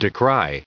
1156_decry.ogg